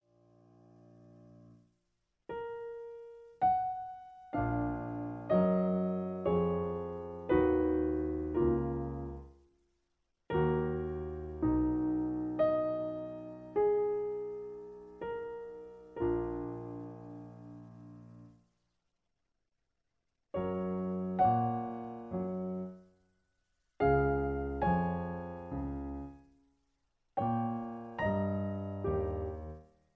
In E flat. Piano Accompaniment